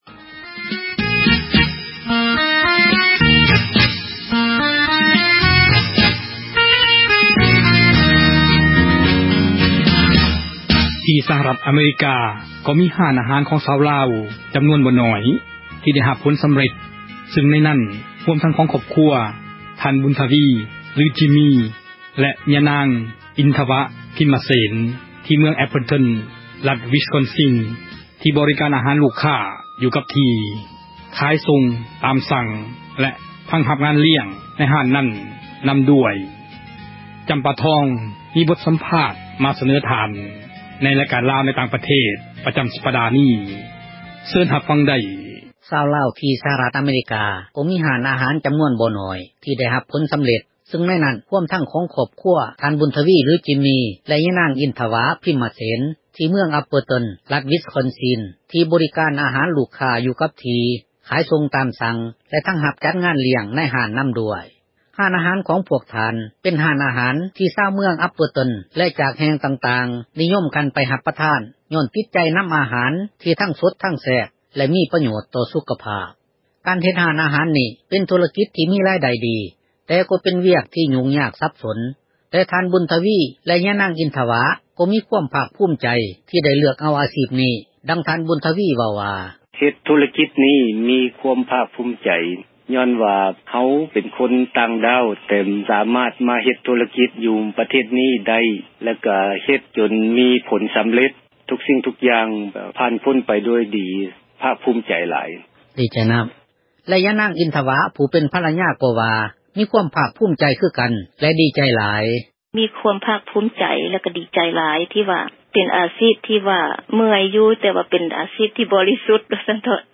ສັມພາດເຈົ້າຂອງຮ້ານອາຫານ ລາວ